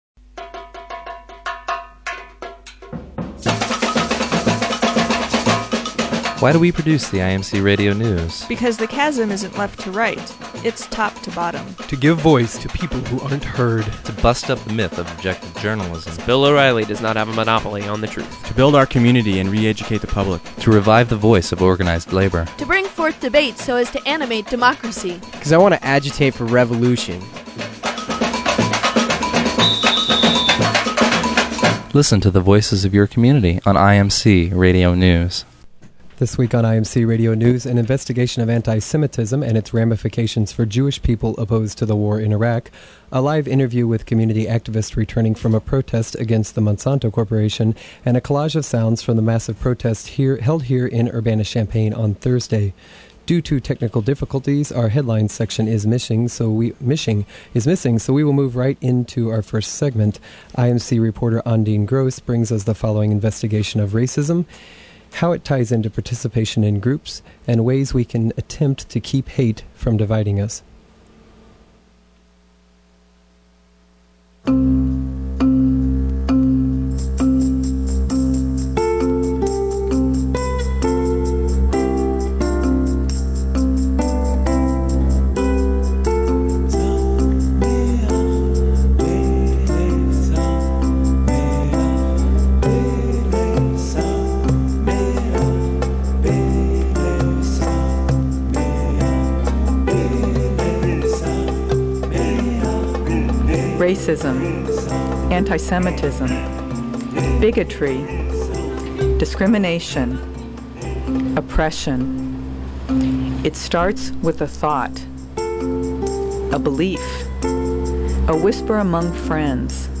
(1) Anti-semitism, it's effects on participation in the anti-war movement, and ways to address it. (2) A live interview with activists returning from protests against Boeing and Monsanto.